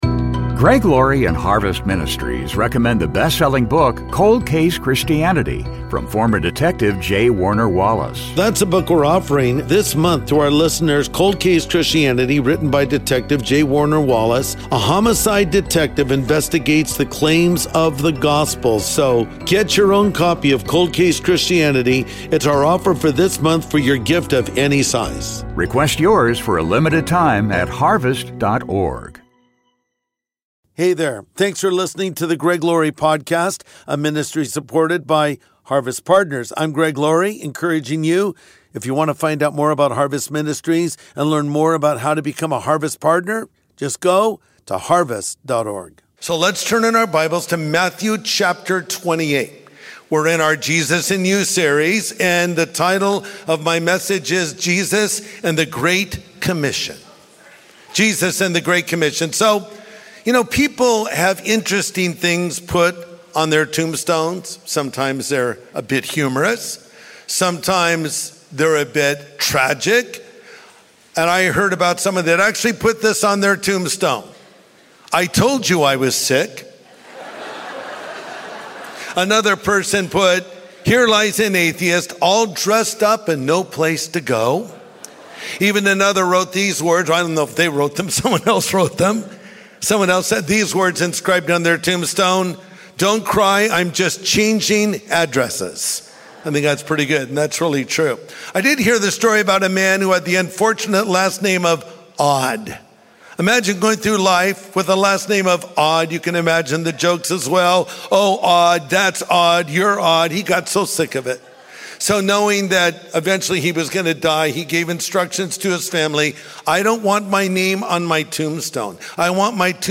Jesus and the Great Commission | Sunday Message Podcast with Greg Laurie
Jesus and the Great Commission | Sunday Message